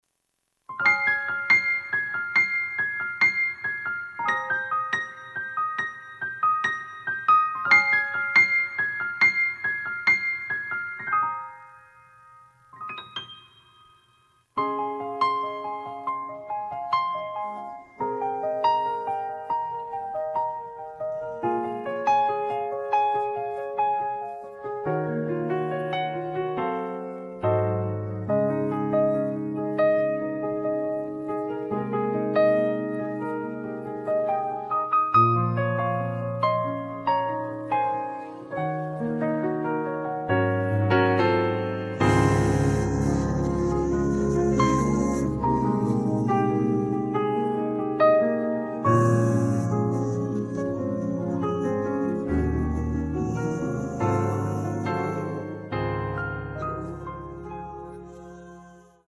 음정 원키 3:19
장르 가요 구분 Voice MR